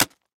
Звуки капусты
Воткнули нож в капусту